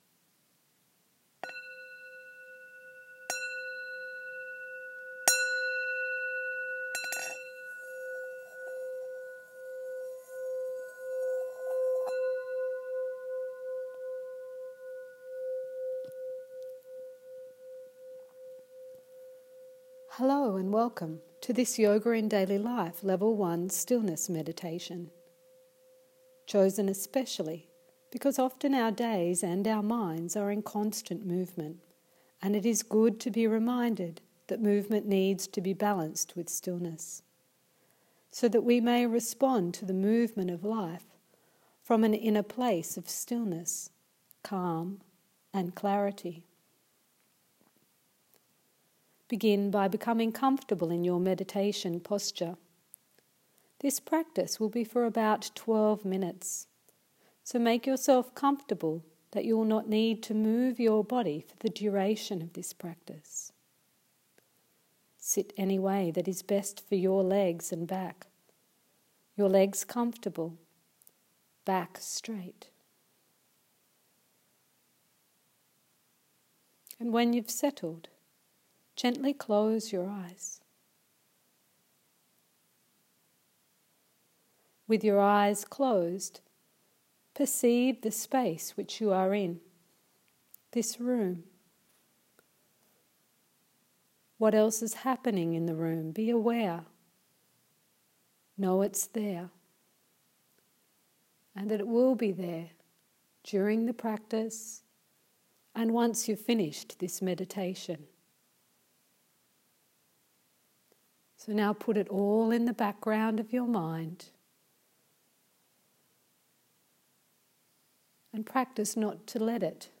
Guided Stillness Meditation
Hello and welcome to this Yoga in Daily Life Level 1 guided stillness meditation.
Notes: Live recording. Female voice with Australian accent.